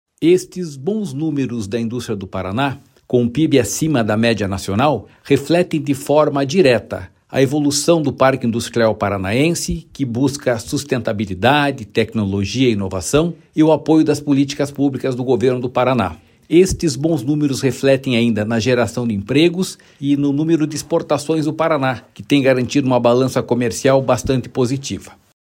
Sonora do diretor-presidente do Ipardes, Jorge Callado, sobre o crescimento da receita da indústria paranaense